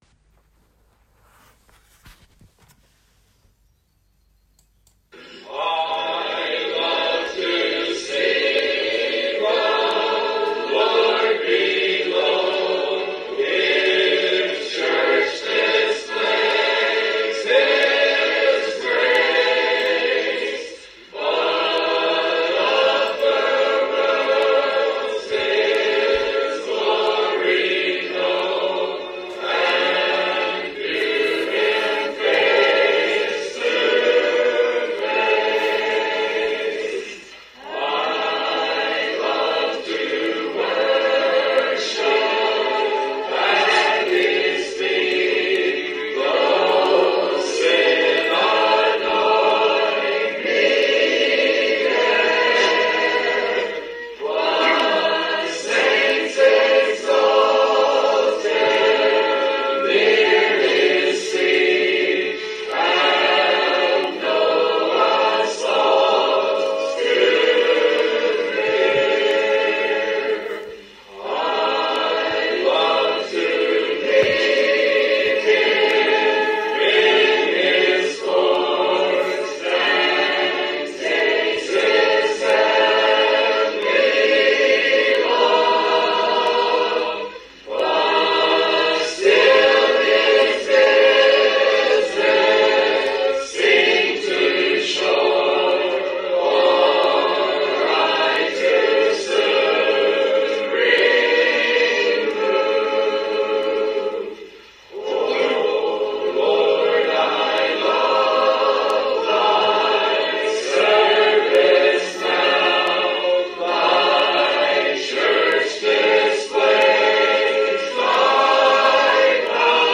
03/29/2020 - Sunday Morning Worship
Sermon or written equivalent